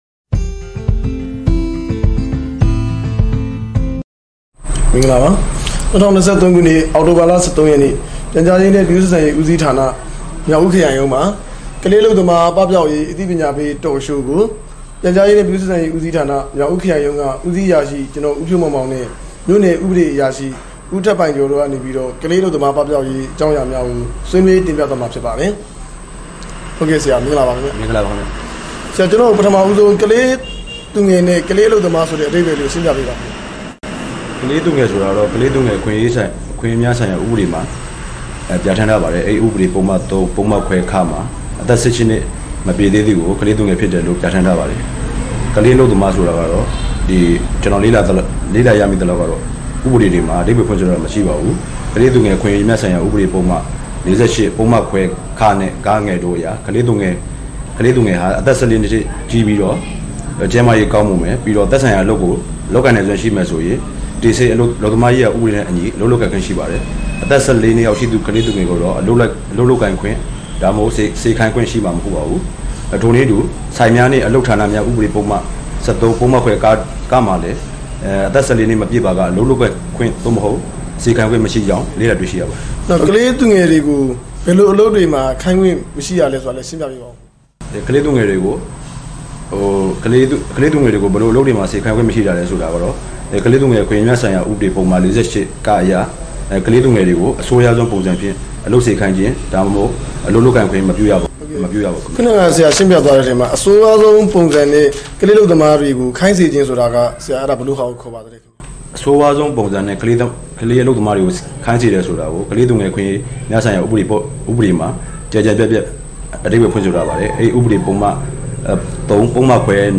ရခိုင်ပြည်နယ်၊မြောက်ဦးခရိုင်ရုံးလူထုအခြေပြုဗဟိုဌာန၌ ကလေးအလုပ်သမားပပျောက်ရေးအသိပညာပေး Talk Show ကျင်းပ